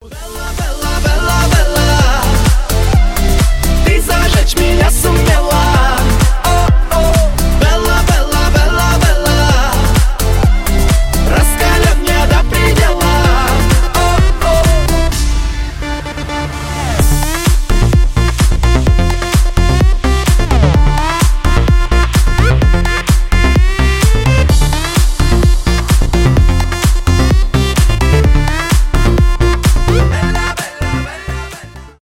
танцевальные
зажигательные